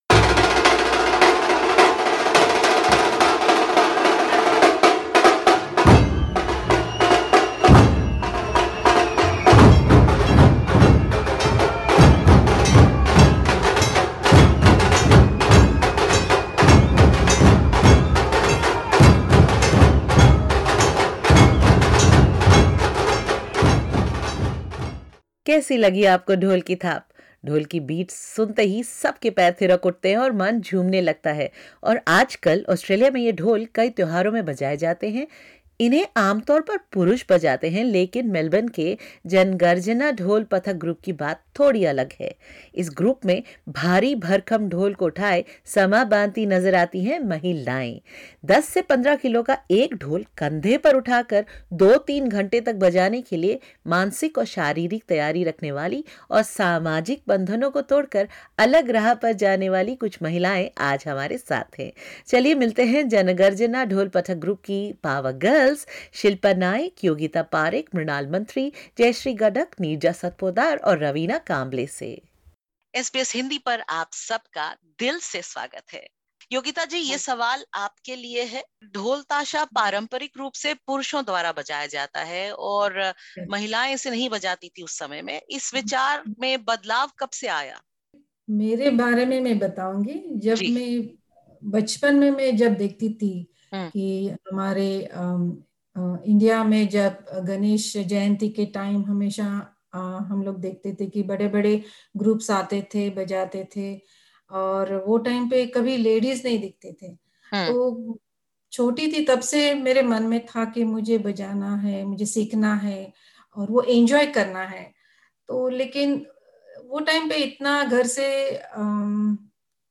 In a field traditionally dominated by men, these female drummers are picking up the sticks and beating stereotypes. Dhol players from the Melbourne-based Jana Garjana Dhol Pathak group speak to SBS Hindi about their experiences as they continue to drum up support to break the bias.